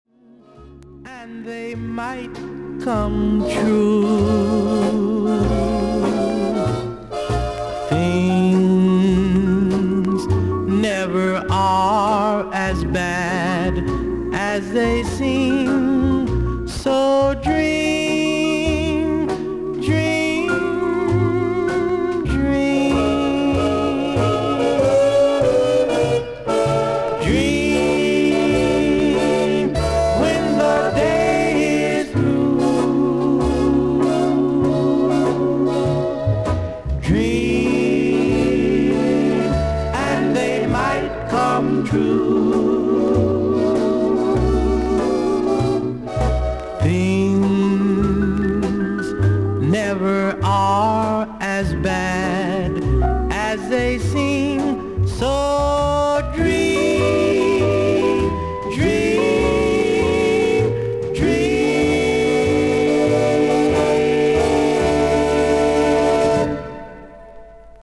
少々軽いノイズあり。
クリアな音です。